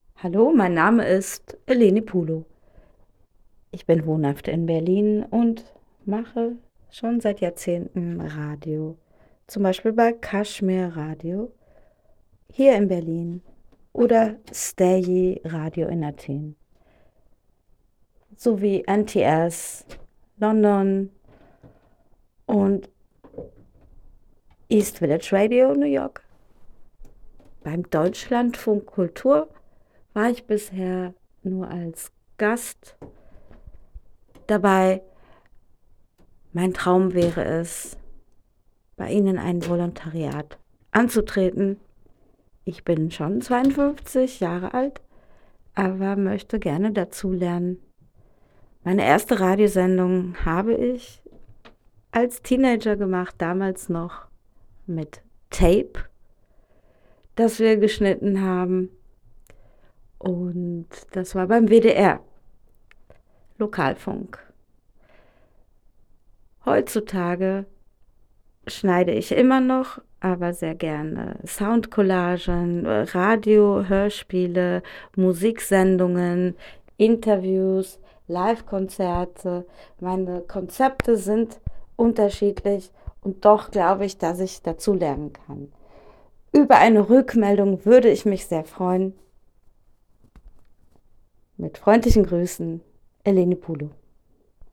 Sprachbeispiel (Deutschlandradio)